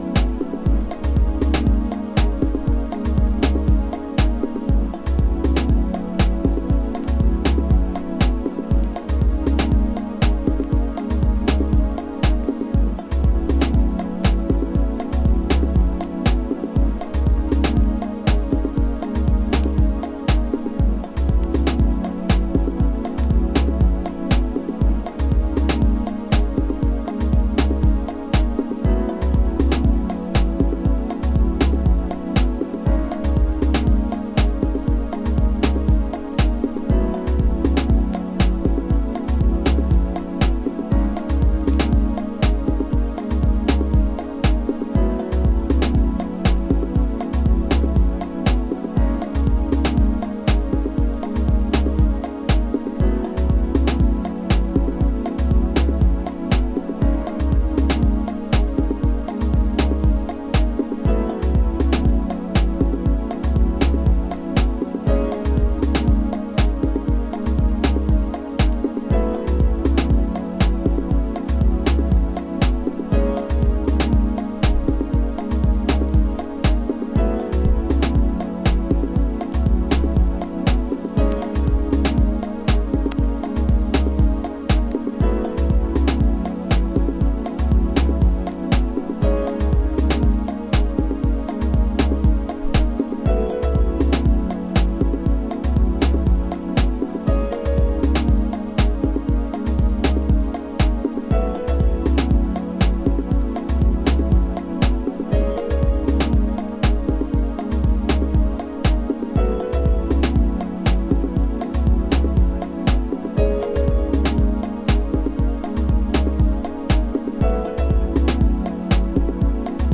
Detroit House / Techno
美しすぎる空間性。